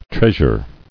[treas·ure]